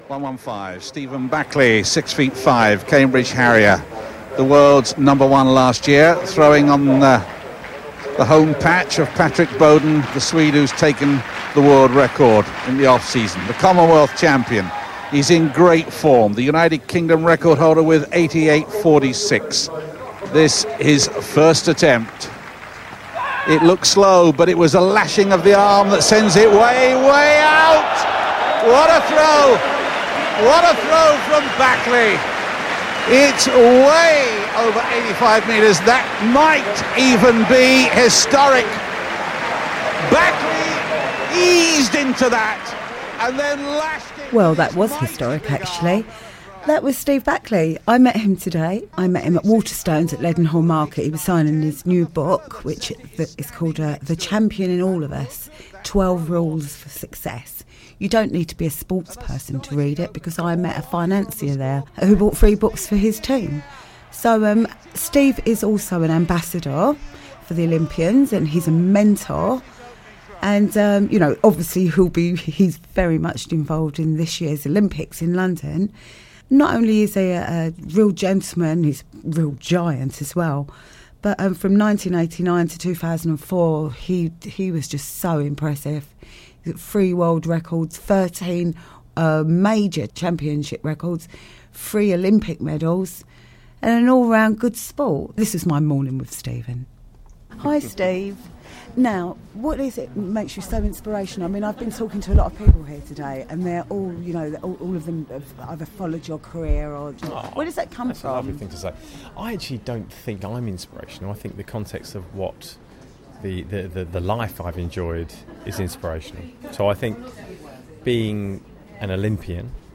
we met at Waterstones, Leadenhall Market London